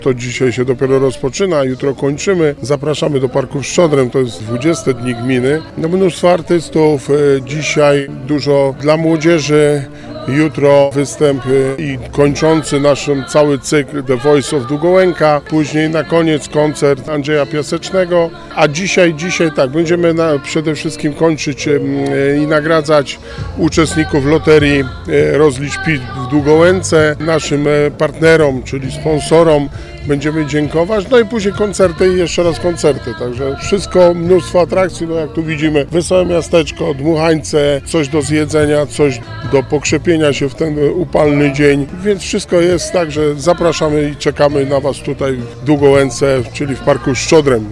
– To święto całej gminy, wszystkich mieszkańców – podkreślił Wojciech Błoński przedstawiając, co zaplanowano dla uczestników wydarzenia.